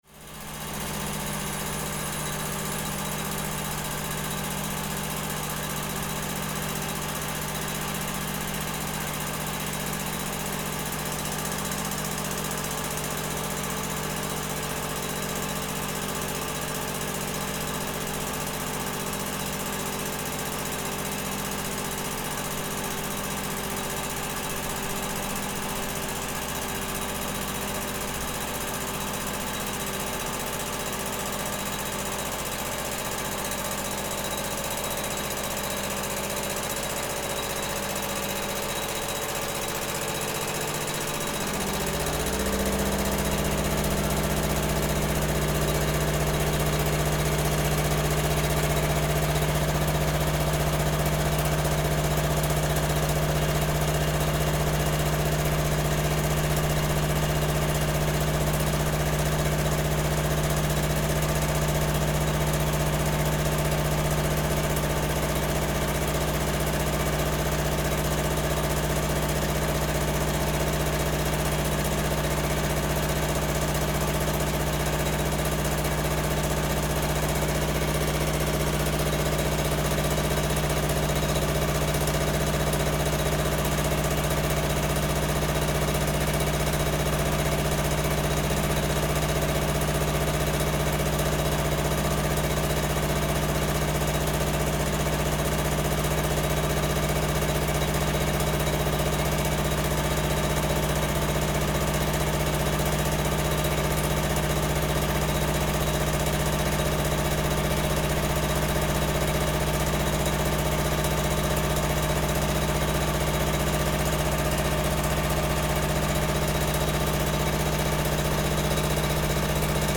Air compressor
Delivers compressed air to a program control that regulates the signals of a foghorn.